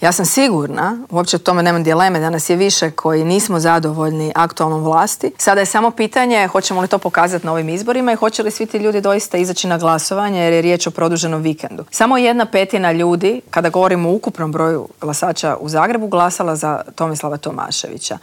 U specijalnom izbornom Intervjuu tjedna Media servisa ugostili smo nezavisnu kandidatkinju za gradonačelnicu Grada Zagreba Mariju Selak Raspudić s kojom smo razgovarali o problemima Zagrepčana.